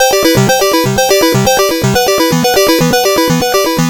Game Over C 123.wav